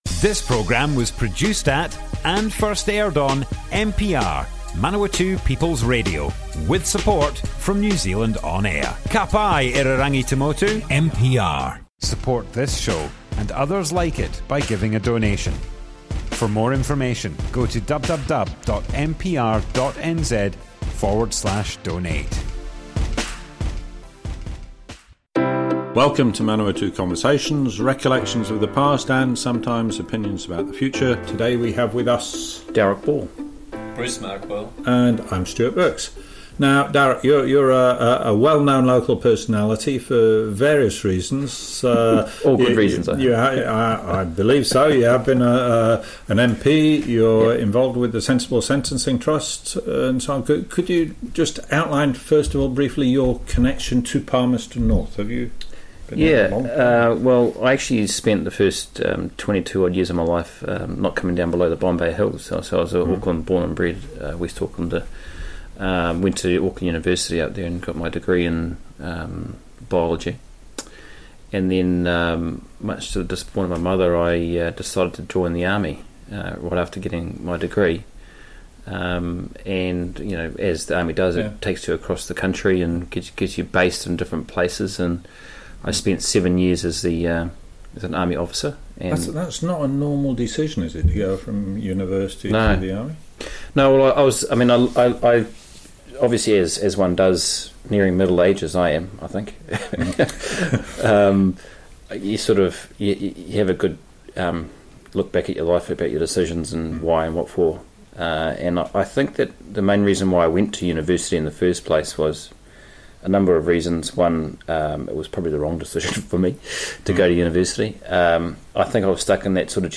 00:00 of 00:00 Add to a set Other Sets Description Comments Darroch Ball Part 1, Early life and time as an MP - Manawatu Conversations Object type Audio More Info → Description Broadcast on Manawatu People's Radio, 25th May 2021.
oral history